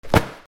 カバンを落とす
/ J｜フォーリー(布ずれ・動作) / J-10 ｜転ぶ　落ちる